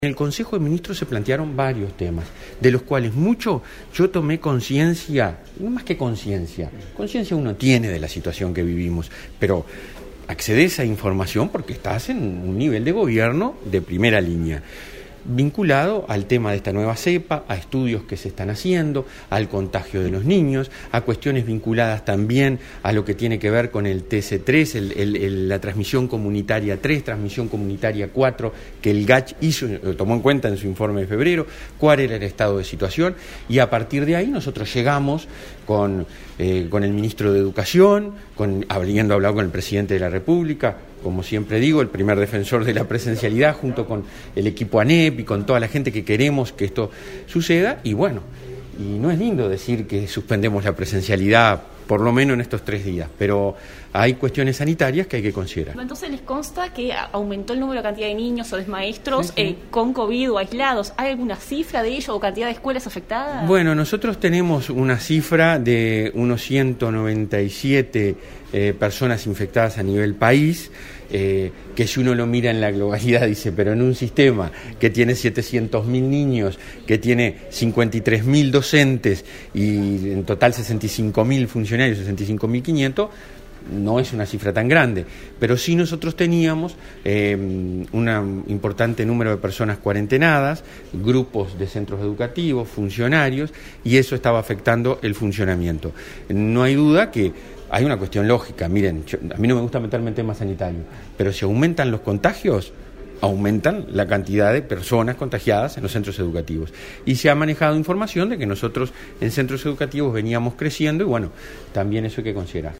El presidente de la ANEP informó que unos 100 mil niños reciben asistencia alimentaria.
En rueda de prensa informó que actualmente hay unas 197 personas infectadas, “que si uno lo mira en la globalidad, no es una cifra tan grande”, pero “teníamos un  importante número de personas cuerentenadas”, consideró Silva.